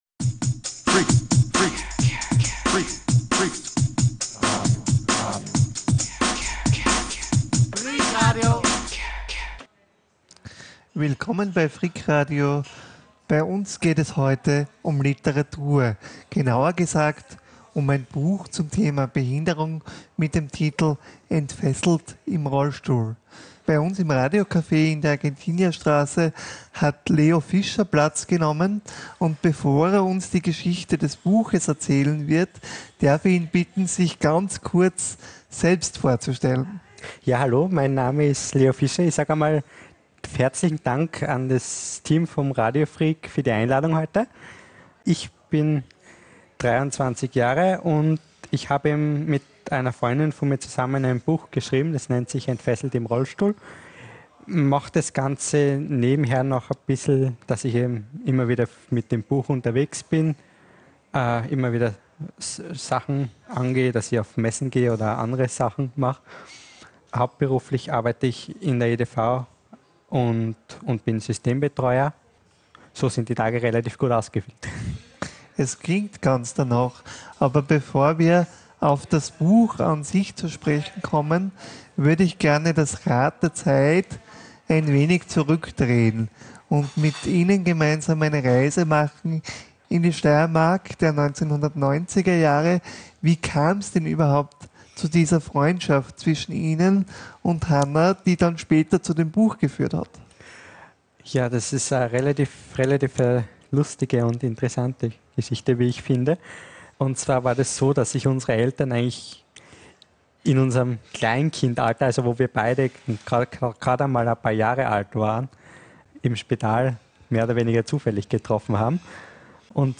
Freak-Radio Interview